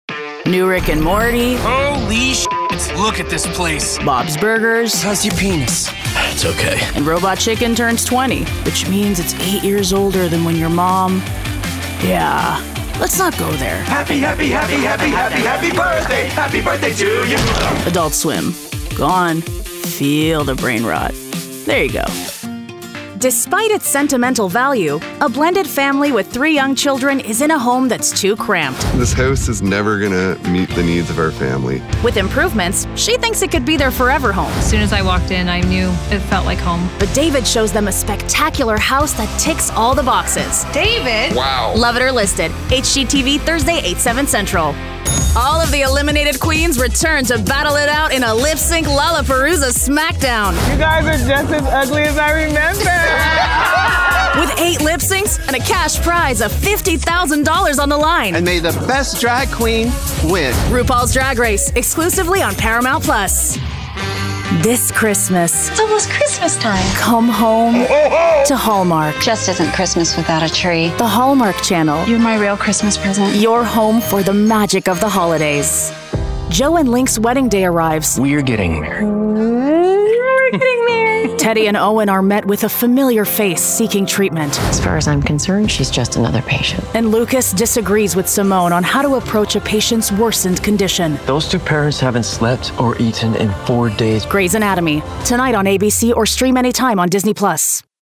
Has Own Studio
tv promos